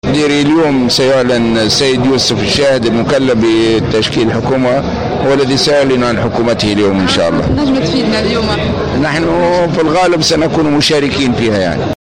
وأشار الغنوشي، في تصريح لمراسلة الجوهرة أف أم، على هامش إشرافه على تظاهرة نظمها شباب النهضة بالجامعة، إلى أن الحركة ستكون "على الأغلب" ممثلة في هذه الحكومة.